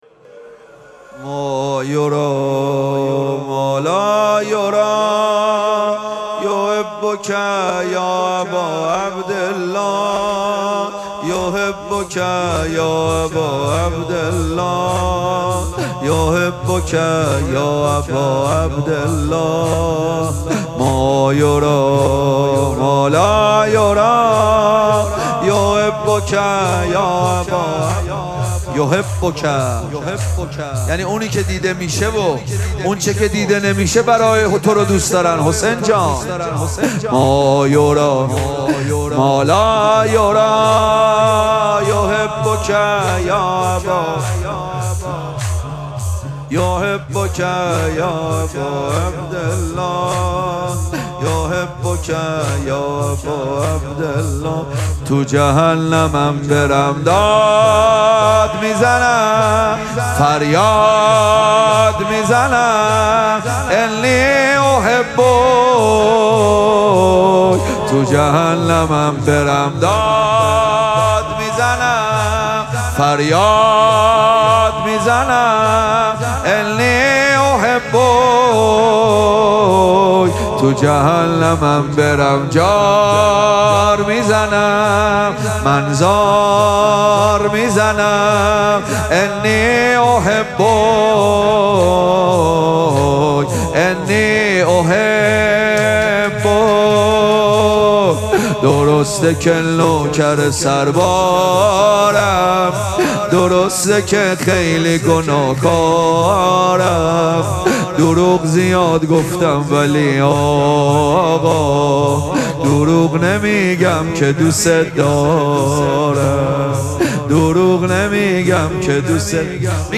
مراسم مناجات شب هفدهم ماه مبارک رمضان